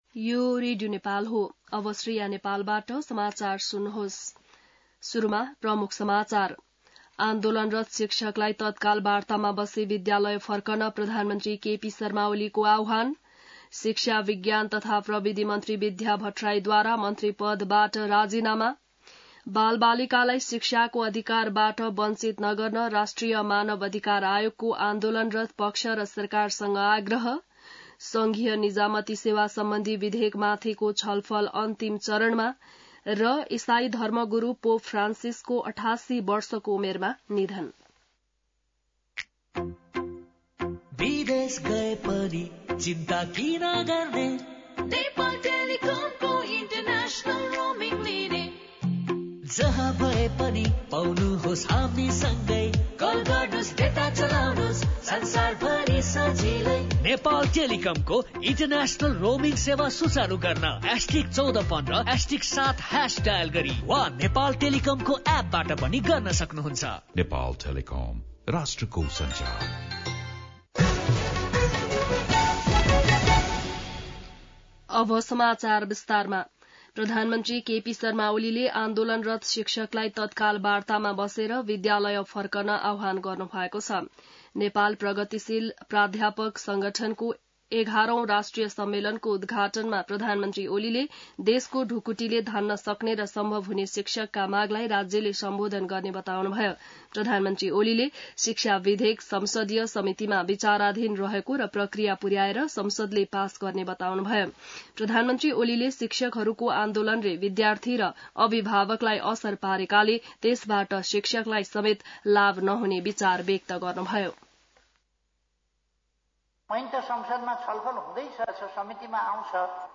बिहान ७ बजेको नेपाली समाचार : ९ वैशाख , २०८२